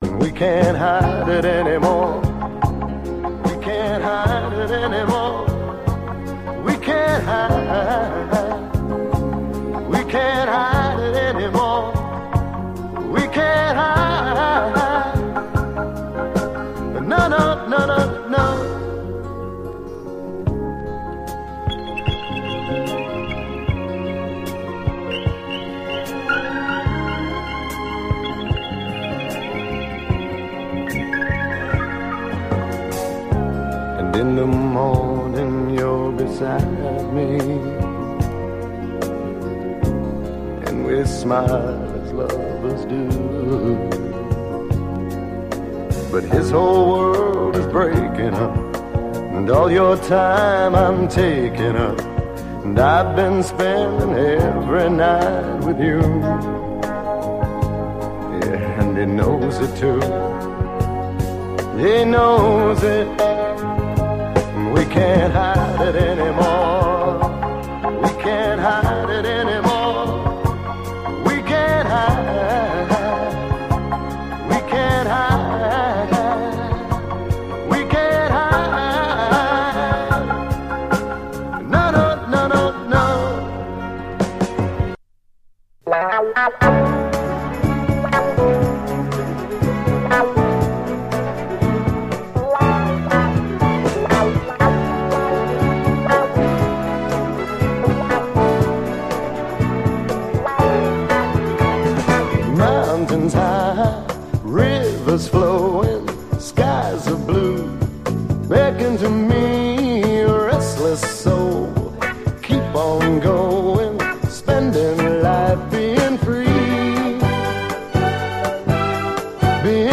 ザクザクしたギター・カッティングから神秘的なムードが漂う、独特の浮遊感を持ったユーロ・モダン・ソウル
ディスコ・フリークに人気のカルト・エロティック・ディスコ